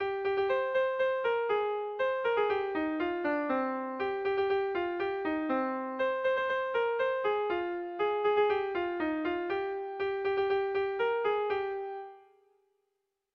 Haurrentzakoa
ABDE